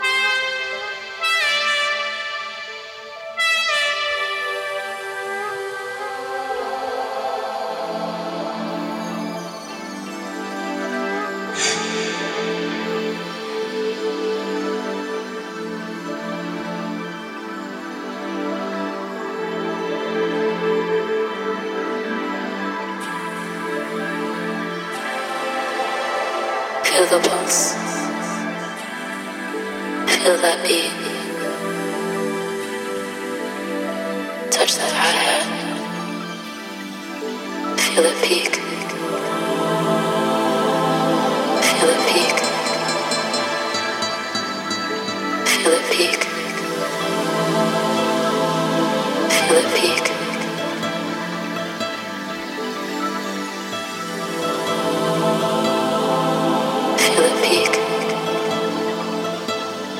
80's〜90'sフィーリングをセンス良く現代的に取り入れながらフロアを熱いエナジーで満たしていく大推薦盤です！